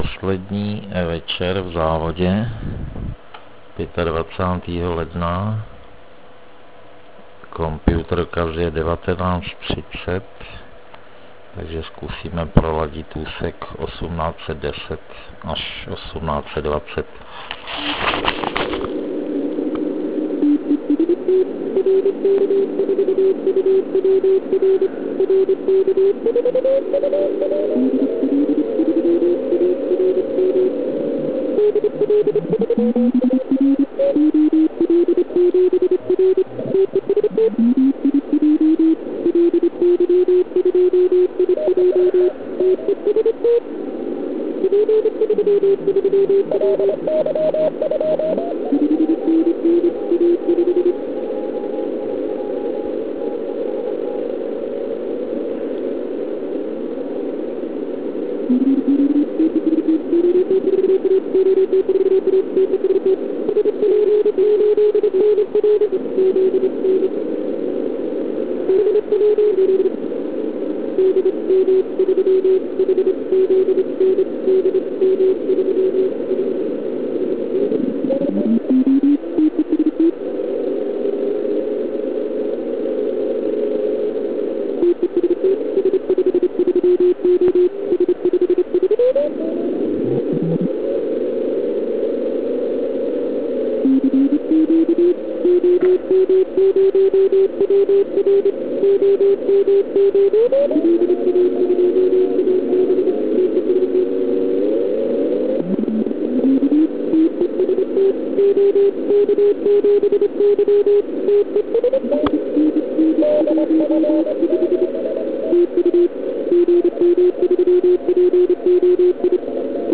Poslouchali jsme oba asi na stejné "pádlové" antény.
Já měl použit filtr 330 Hz a nastaven offset 400 Hz (výška zázněje).